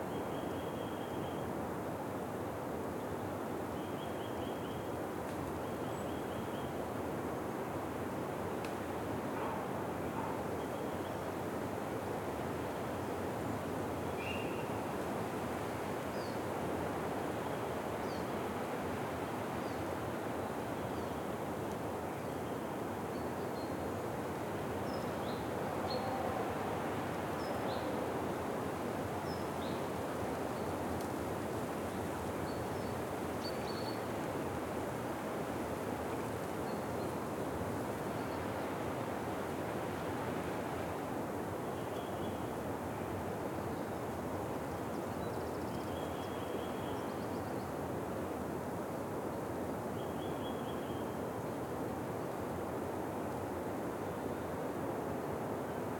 ambience-birds-forest-quiet-wind-loop-02.ogg